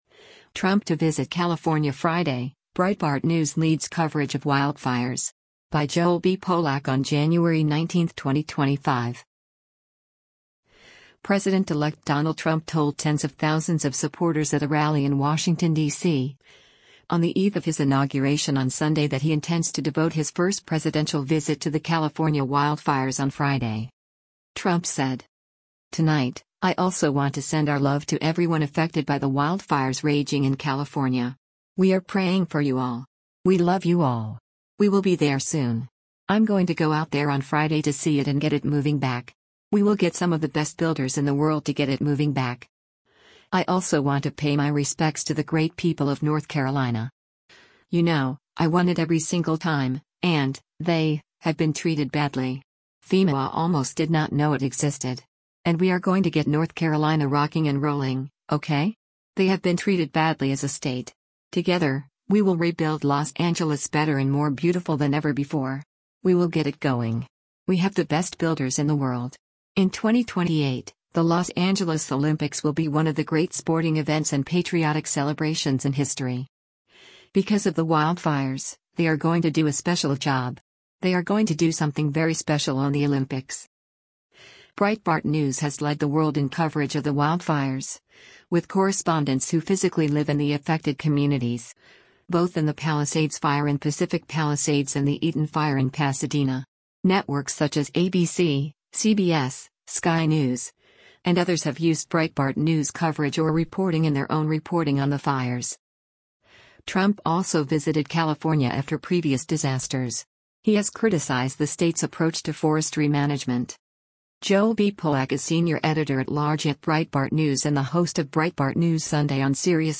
President-elect Donald Trump told tens of thousands of supporters at a rally in Washington, DC, on the eve of his Inauguration on Sunday that he intends to devote his first presidential visit to the California wildfires on Friday.